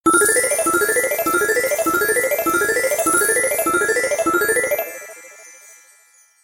bonus_win_transfer.mp3